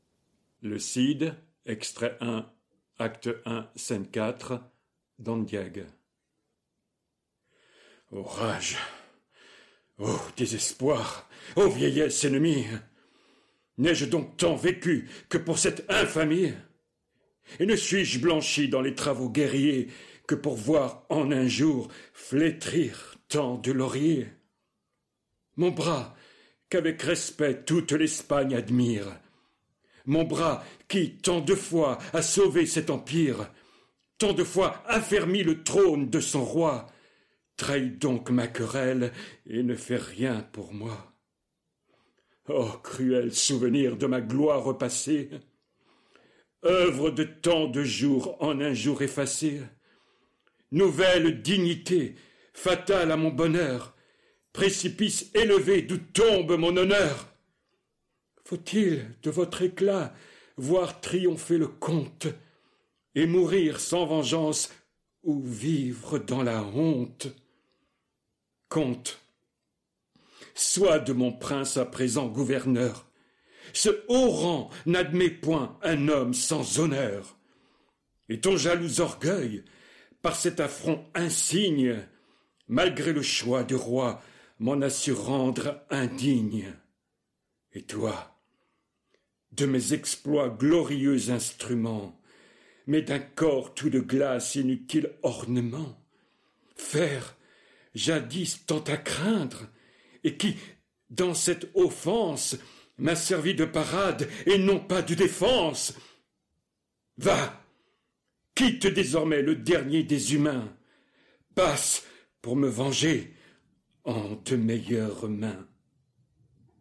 lue par un comédien.